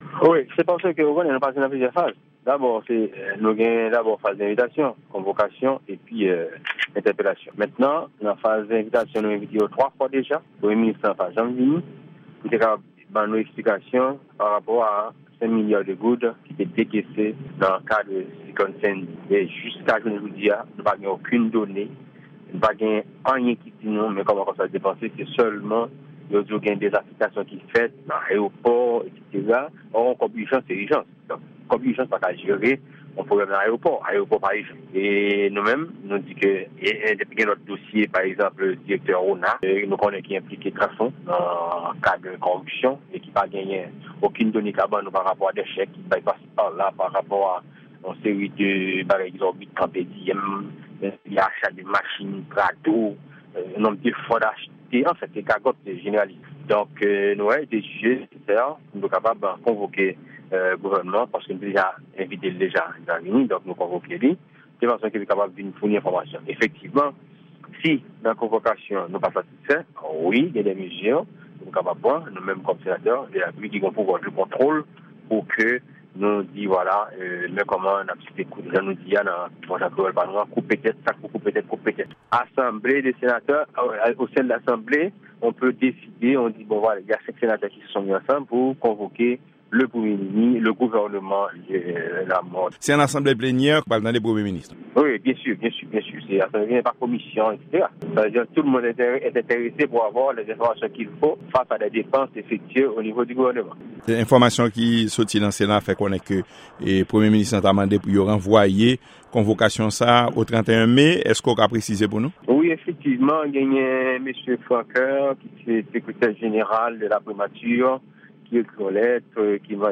Deklarasyon senatè Francisco Delacruz